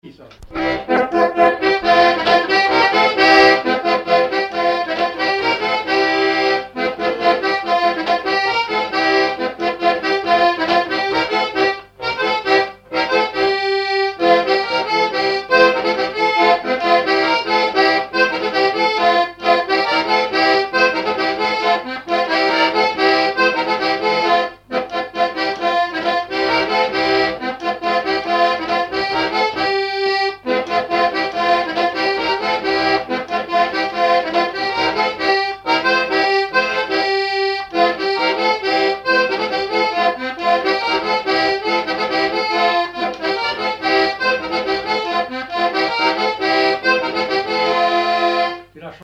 Fonction d'après l'analyste danse : ronde : grand'danse ;
Fonction d'après l'informateur danse : branle : courante, maraîchine ;
Genre laisse
Catégorie Pièce musicale inédite